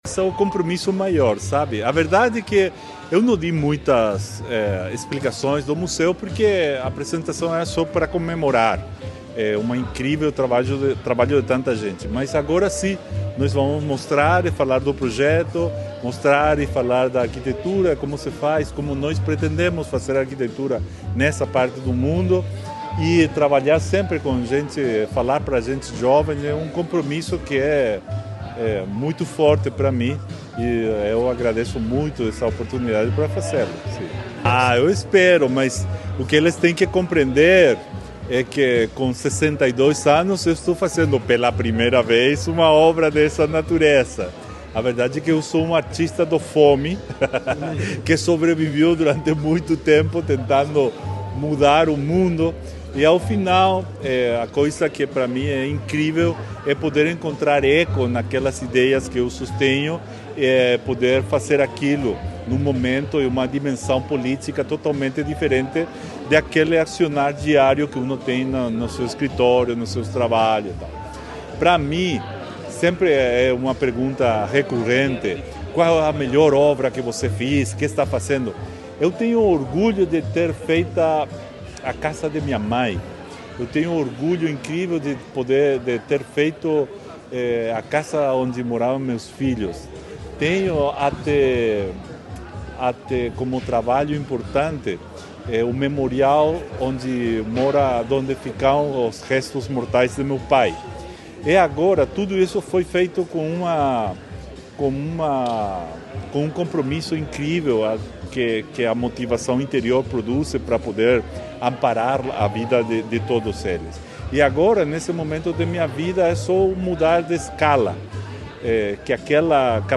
Sonora do arquiteto responsável pela concepção do Centre Pompidou Paraná, Solano Benitez, sobre a masterclass para profissionais da área em Foz